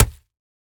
Minecraft Version Minecraft Version 1.21.5 Latest Release | Latest Snapshot 1.21.5 / assets / minecraft / sounds / block / packed_mud / break5.ogg Compare With Compare With Latest Release | Latest Snapshot
break5.ogg